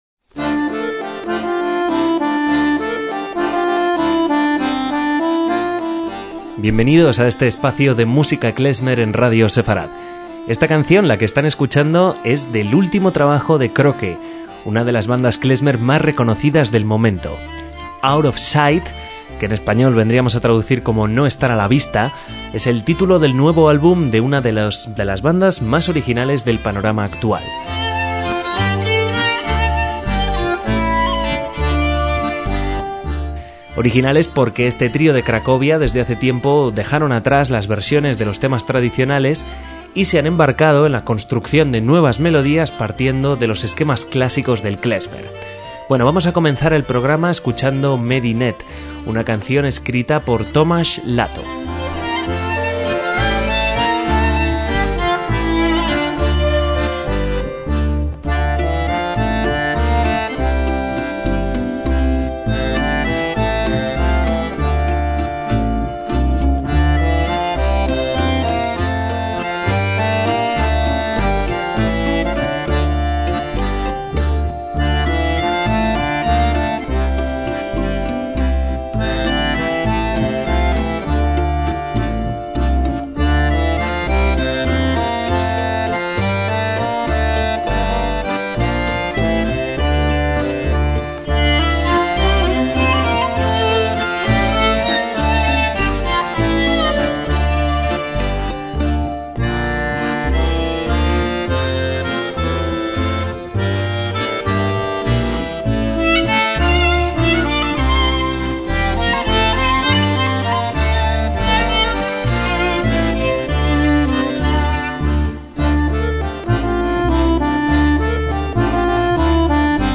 MÚSICA KLEZMER
contrabajo
viola
acordeón